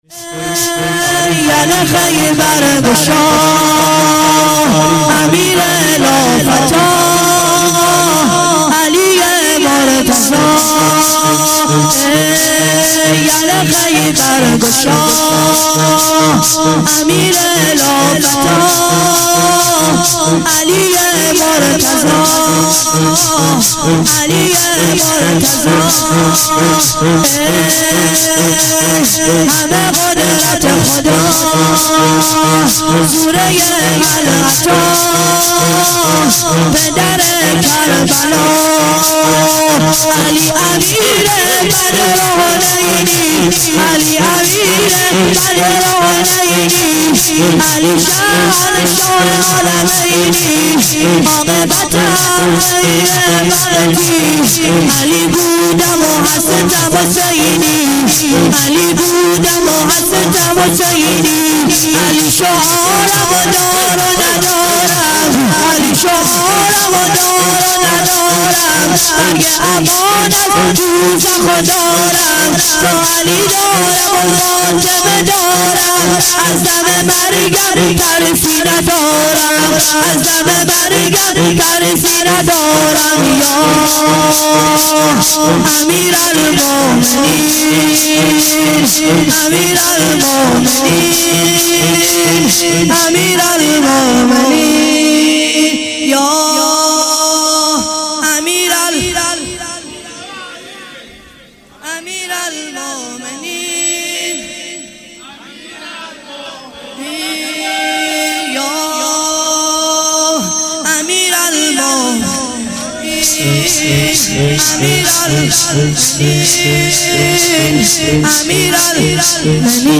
شور - ای یل خیبر گشا